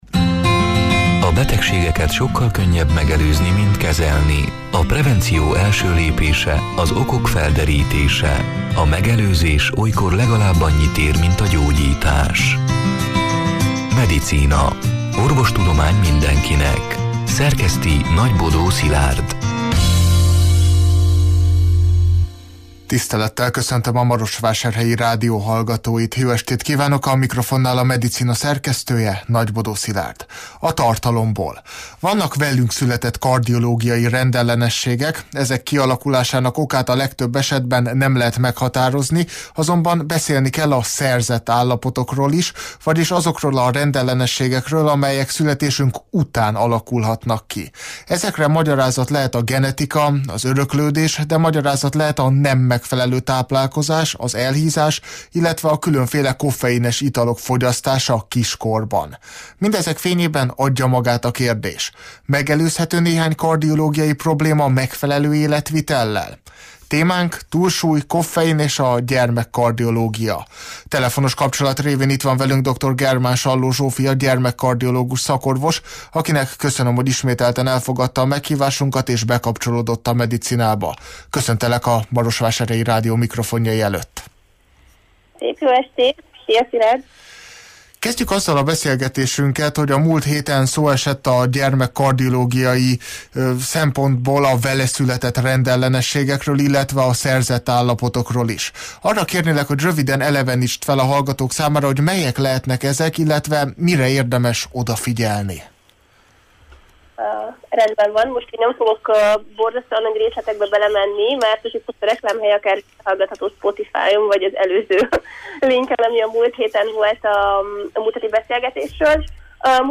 A Marosvásárhelyi Rádió Medicina (elhangzott: 2024. október 30-án, szerdán este nyolc órától élőben) c. műsorának hanganyaga: